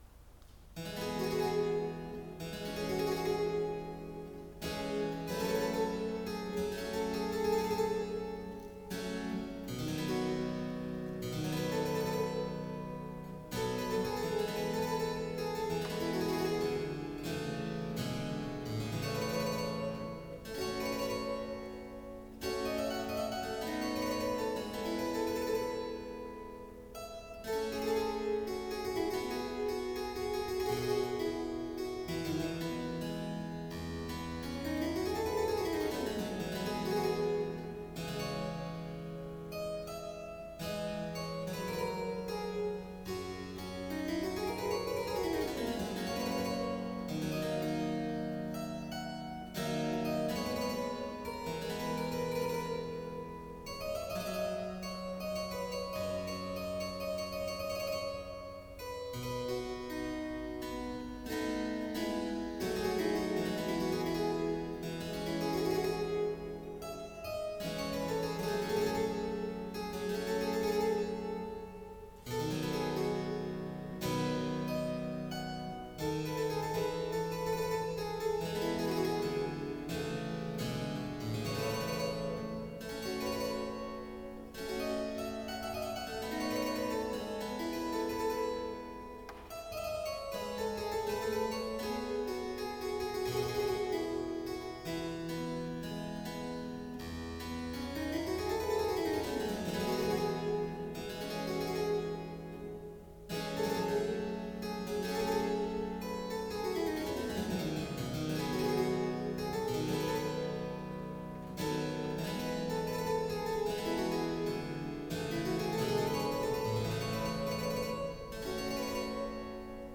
clavecin et orgue
Quelques pièces de clavecin et d’orgue